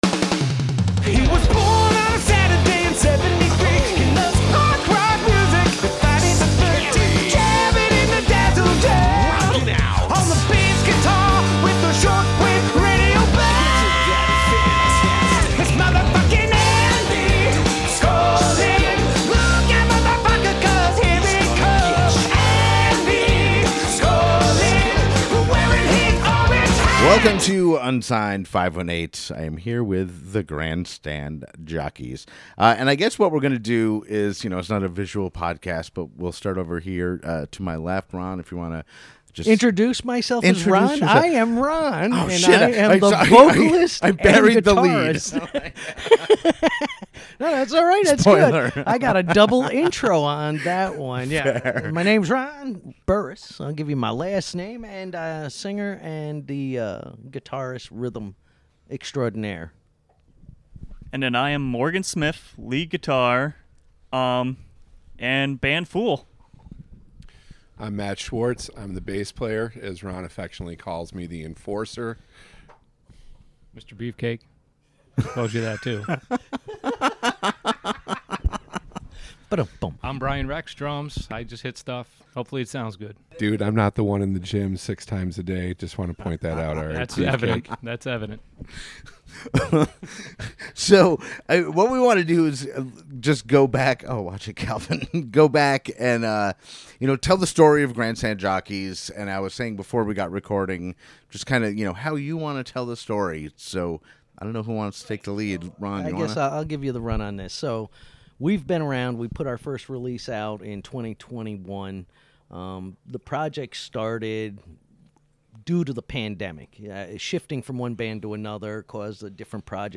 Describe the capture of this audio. The Grandstand Jockey stopped by and we not only talked about the local and regional music scenes, and many other things, but they also played a song live in The Dazzle Den.